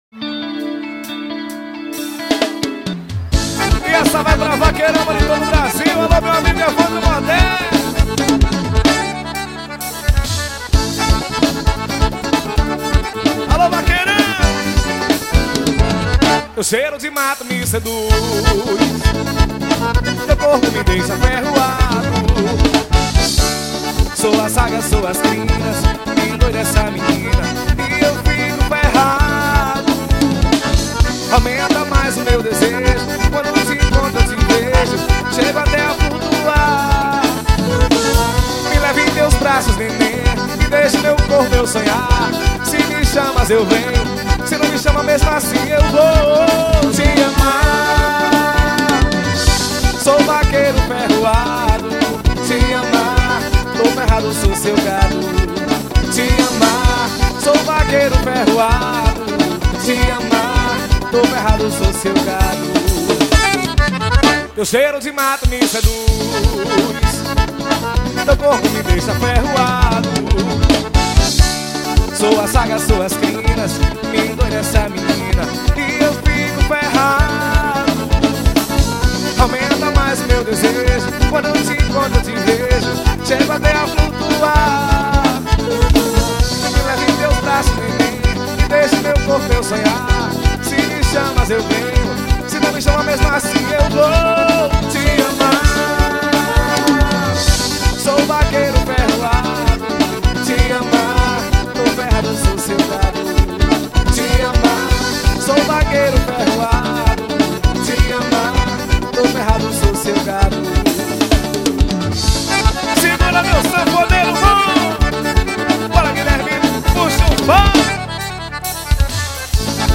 Composição: FORRÓ.